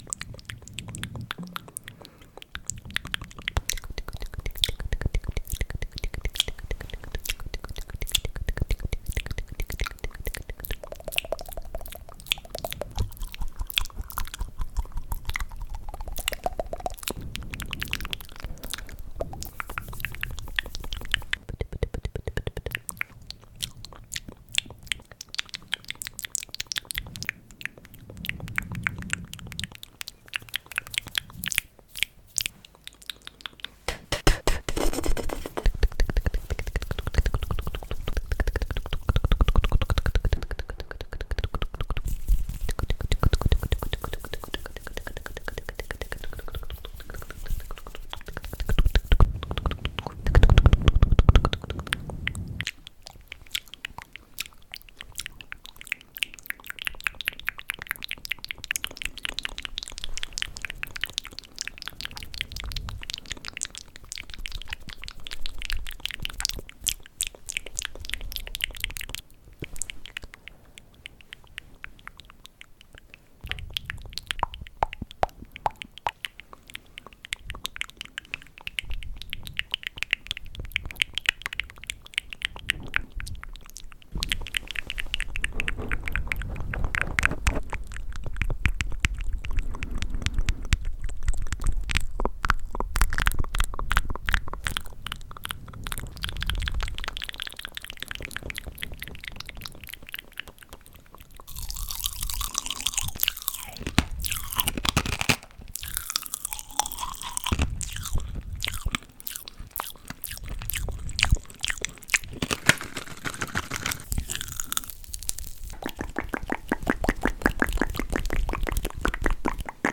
Свист, щелчки языком, цоканье, шепот, мычание и другие необычные звуковые эффекты.
Звуки изо рта девушки